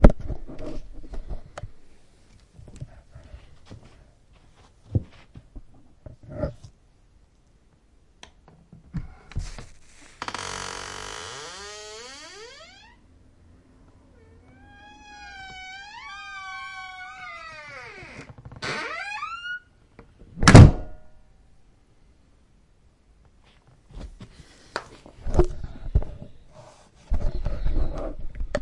家庭录音 " 门吱吱响
描述：关门时门尖叫的声音
Tag: 打开 关闭 关闭 尖叫 伦敦 房子